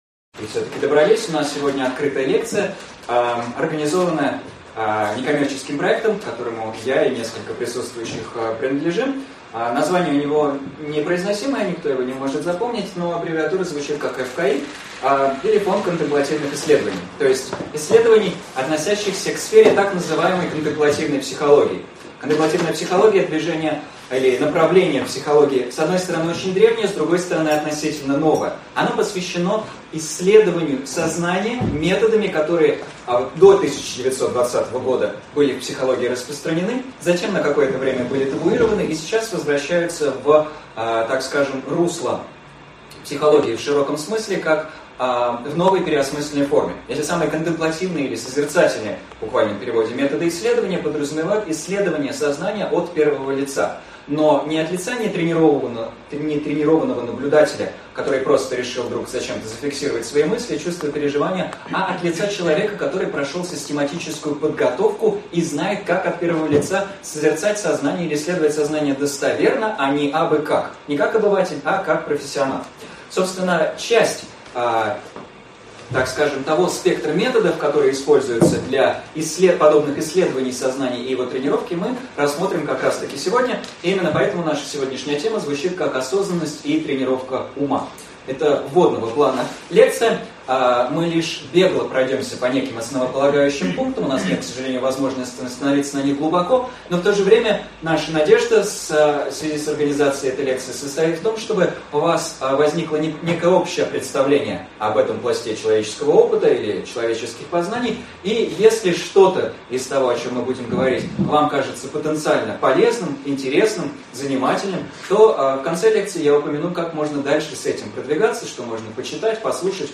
Аудиокнига Осознанность и тренировка ума | Библиотека аудиокниг